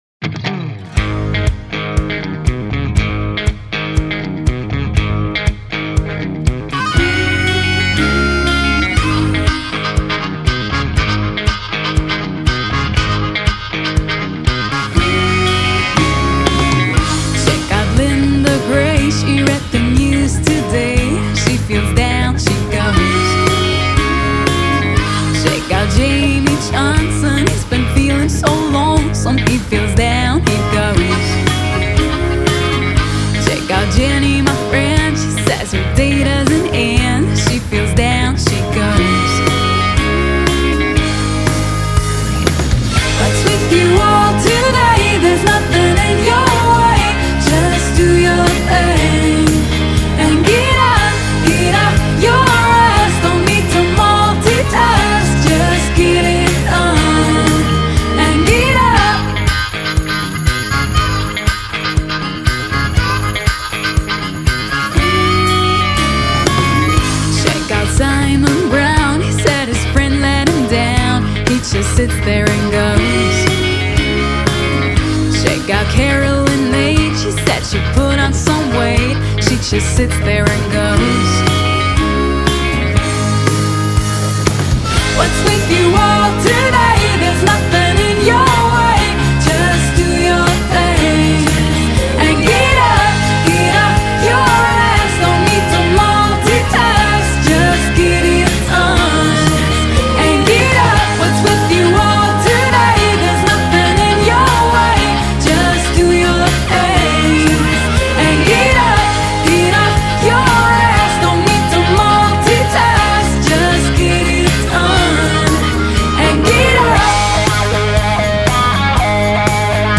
Pop / Country.